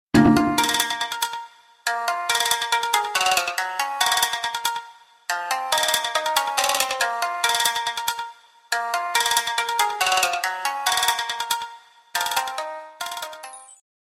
• Качество: 128, Stereo
забавные